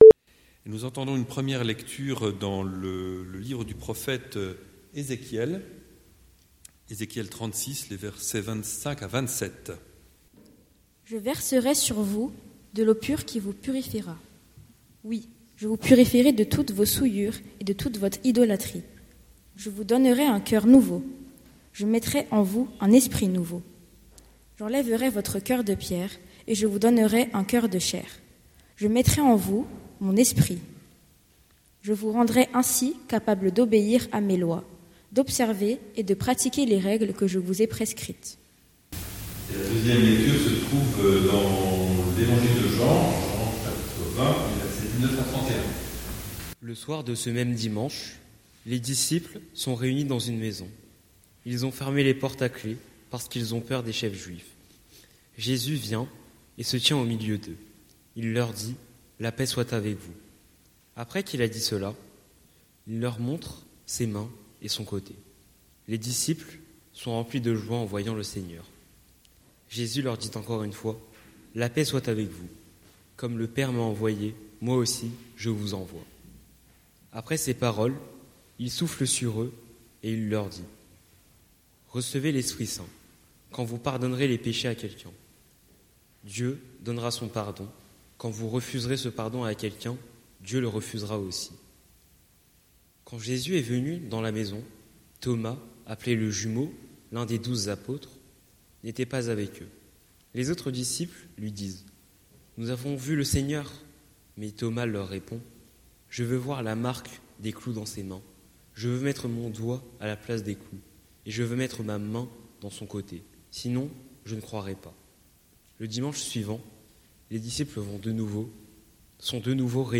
Prédication du 12/04/2026